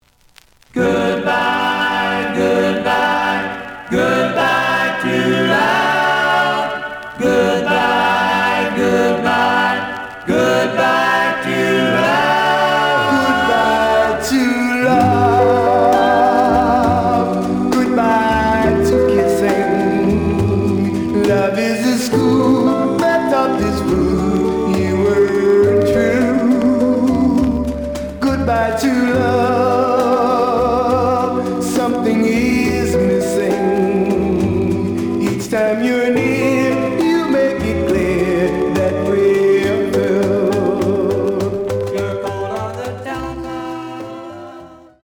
The audio sample is recorded from the actual item.
●Genre: Rhythm And Blues / Rock 'n' Roll
Slight off-center on B side.